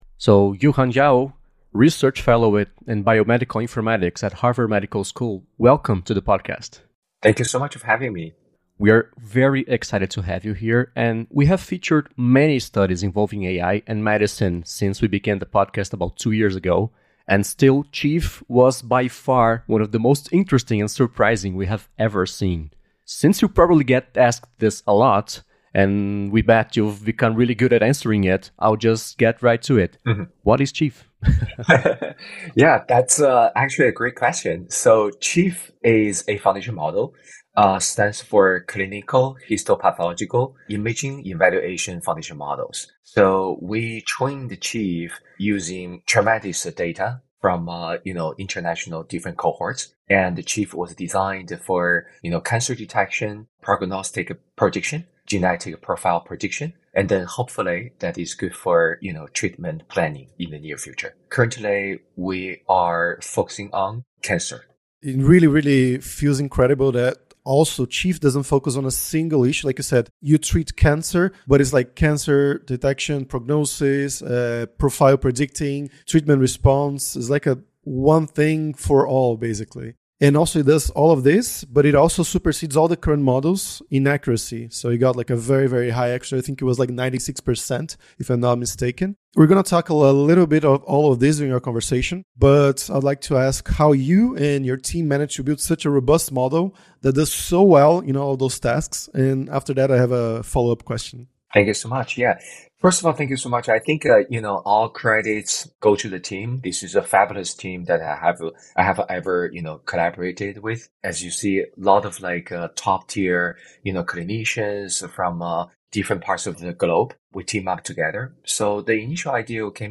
Entrevista original em inglês